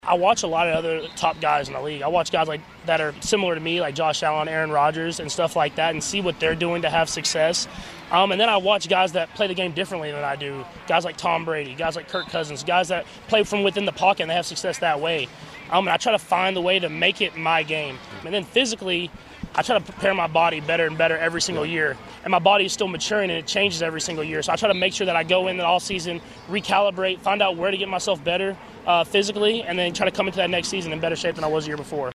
Quarterback Patrick Mahomes says he learns a lot from watching film.